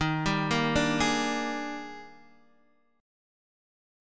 EbM11 Chord
Listen to EbM11 strummed